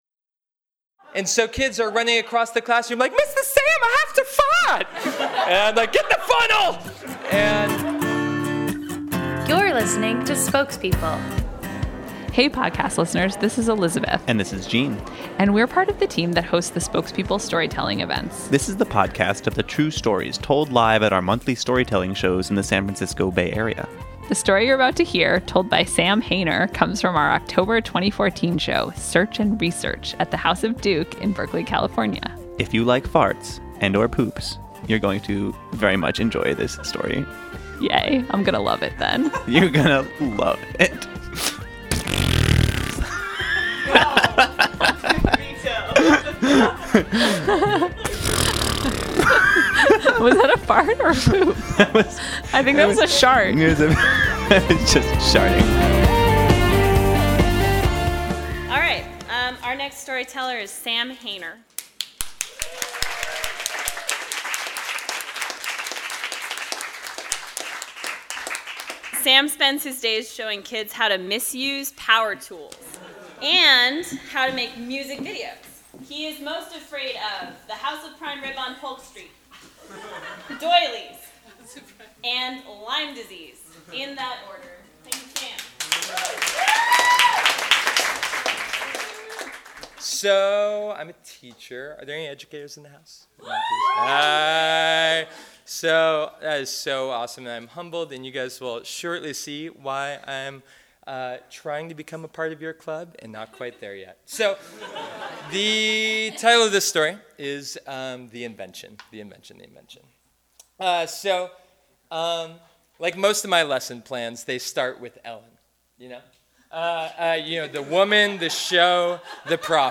One budding inventor builds a machine to channel his own hot air into a means to blow away his elementary school enemies. This story was told live at our Oct. 2014 show, "Search and Research."